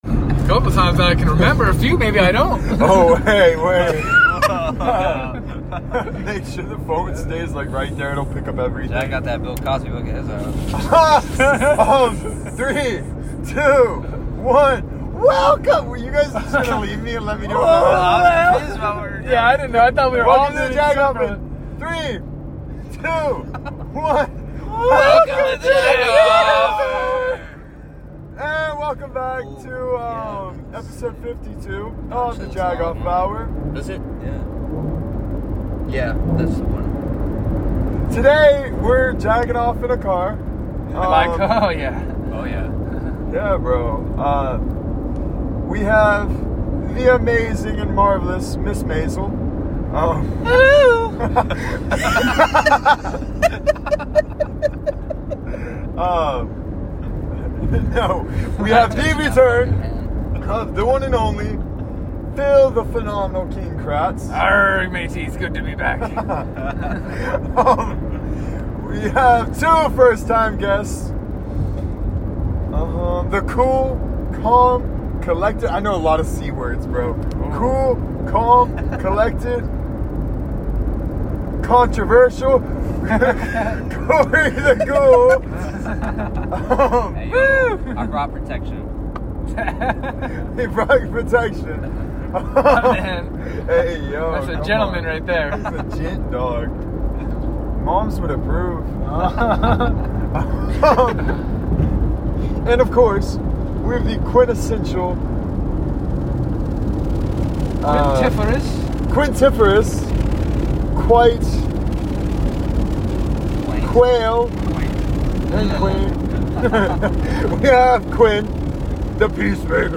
On this fun episode we enjoy our car ride, munch, & nerd out over the upcoming fights & have a wholesome talk relating to our adventures.